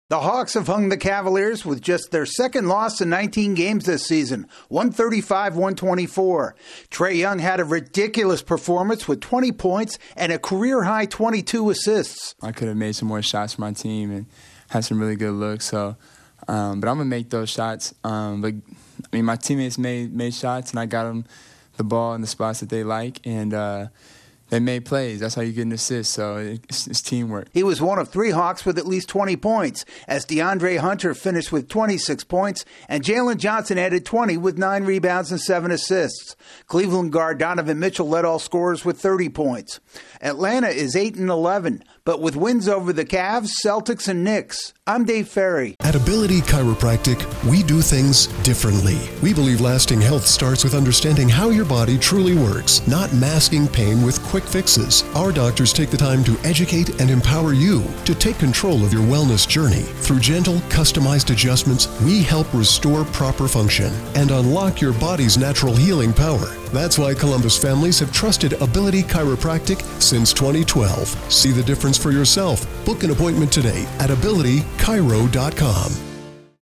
A pass-happy Hawks guard leads a win over the NBA's top team. AP correspondent